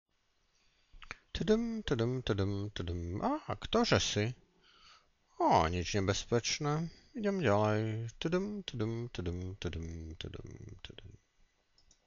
Hlas tigra.WMA